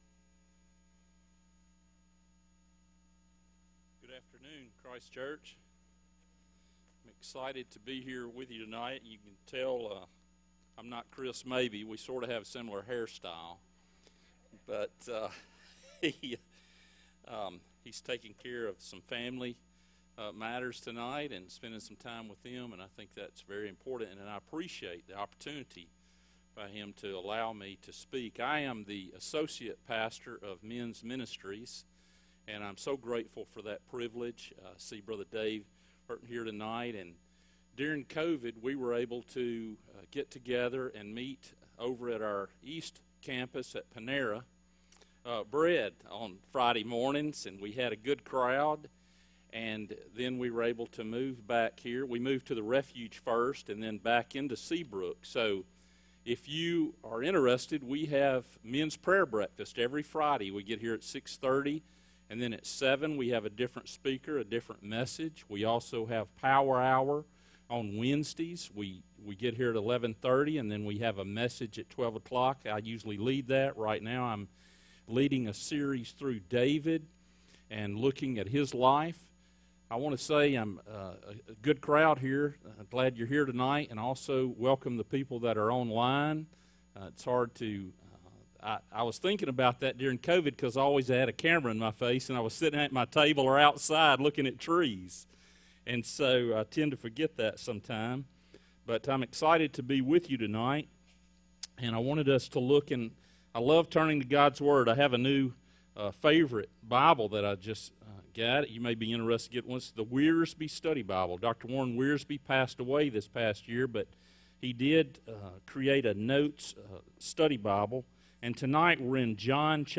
From Series: "Standalone Sermons "